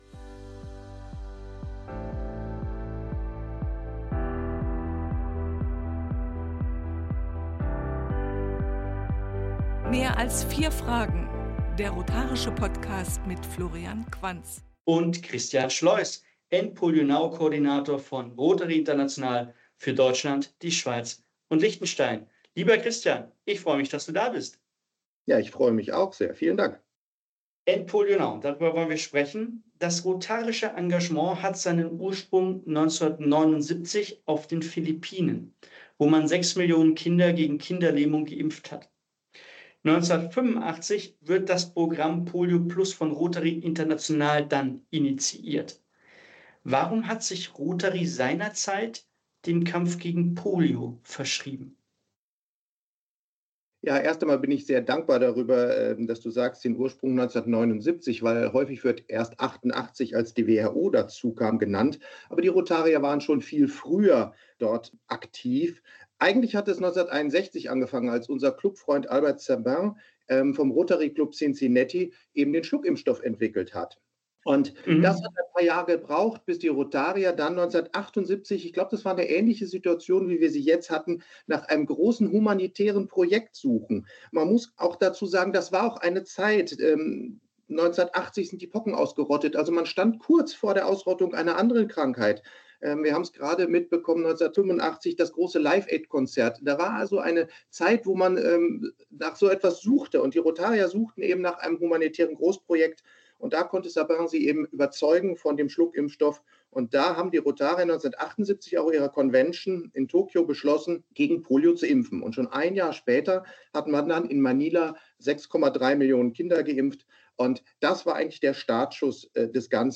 Diesmal im Gespräch